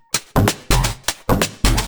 Index of /VEE/VEE2 Loops 128BPM
VEE2 Electro Loop 140.wav